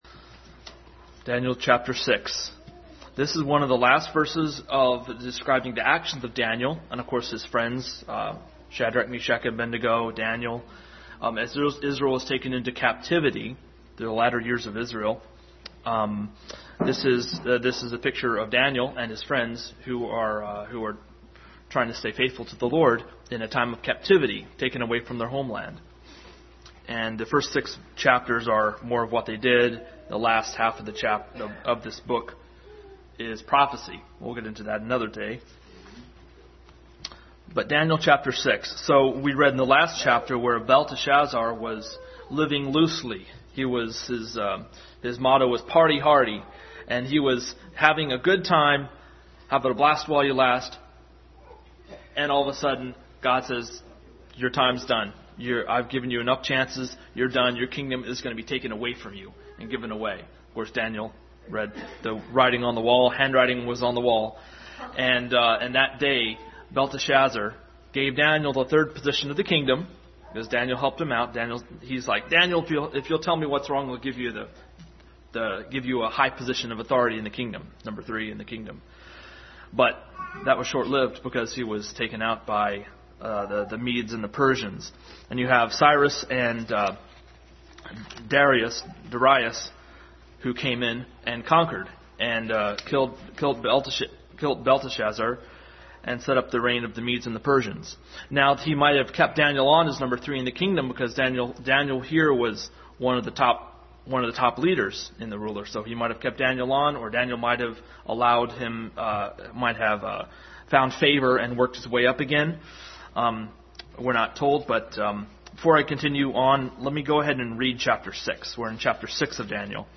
Passage: Daniel 6:1-28, Hebrews 6:10, 11:6, Psalm 1, 119:11, 63:1-11 Service Type: Sunday School